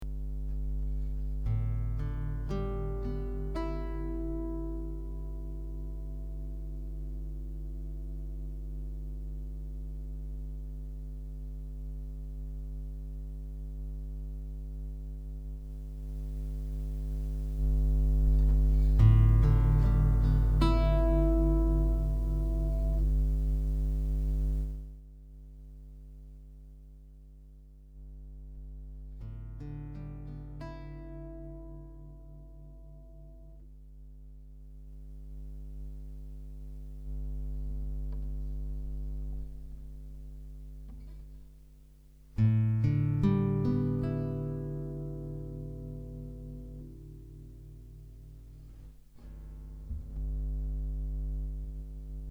Подключена только карта к ноуту и гитара в 1 канал.
Пример снизу: Гейн на карте 30, 40, 20 и последний когда держу разъем одной рукой, а вторая на струнах.